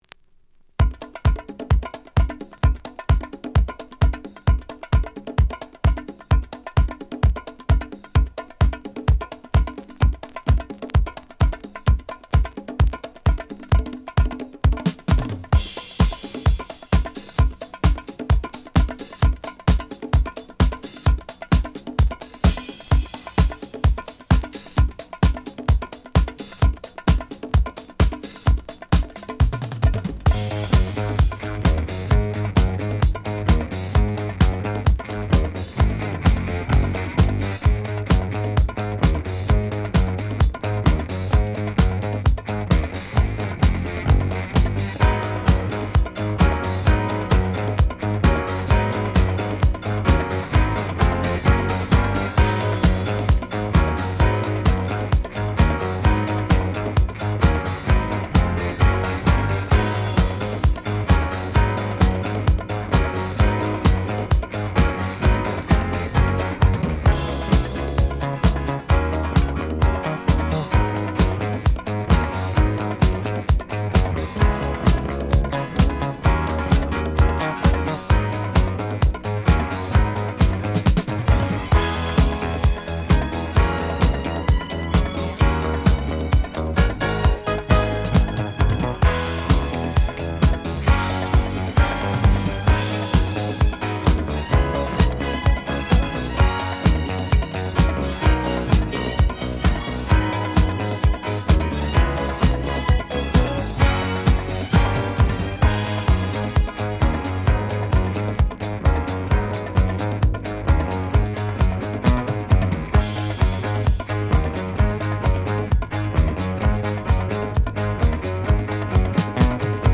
Genre: Disco.